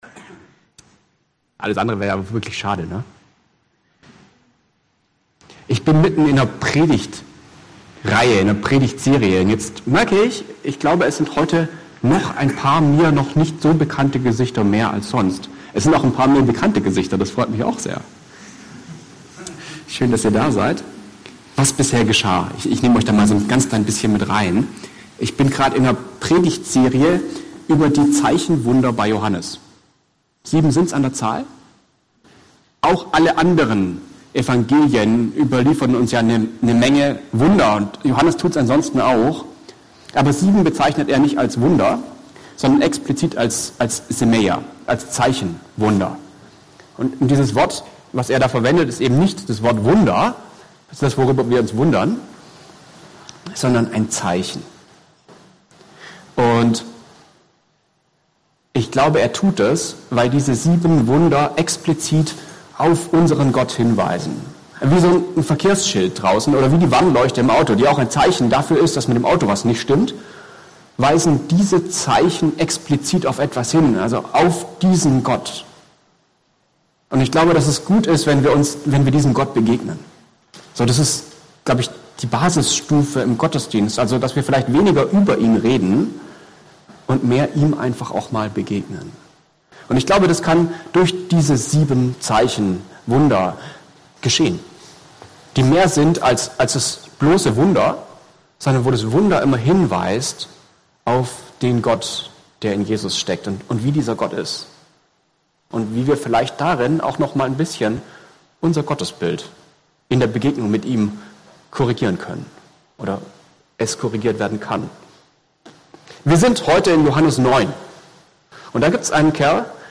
Passage: Johannes 9 Dienstart: Segnungsgottesdienst